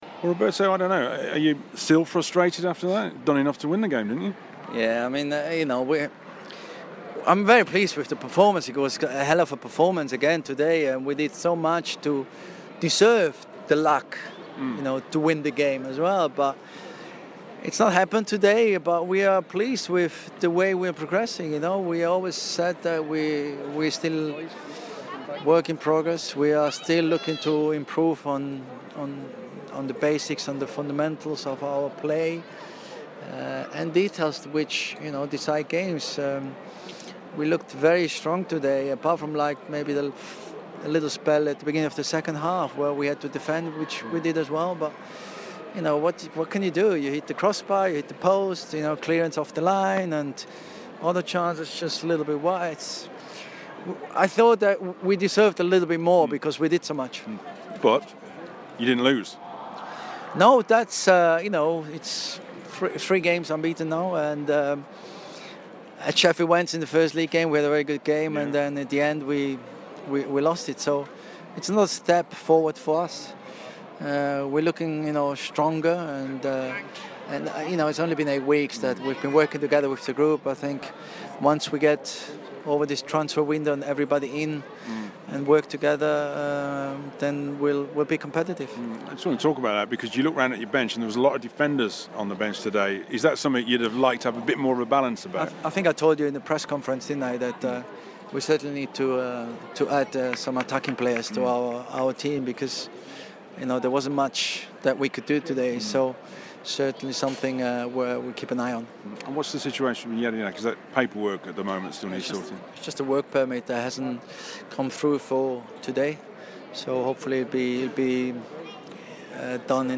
Villa boss Roberto di Matteo speaks to BBC WM after a 0-0 draw with Derby at the iPro Stadium.